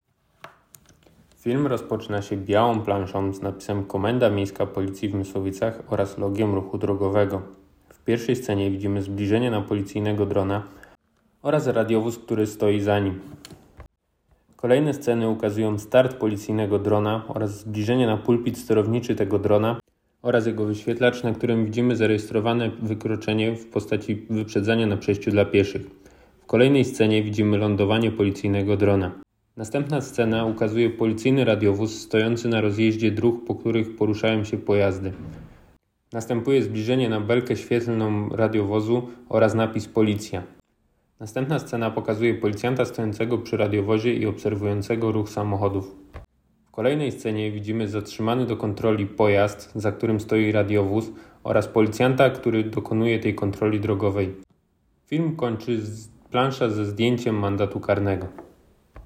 Opis nagrania: audiodeskrypcja do filmu z akcji bezpieczny pieszy przy użyciu drona